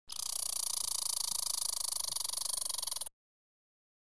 Eichhörnchen Geräusche umfassen eine Palette von Klängen, die je nach Situation und Bedarf variieren.
Eichhörnchen Geräusche
Eichhoernchen-Geraeusche-pixabay-2.mp3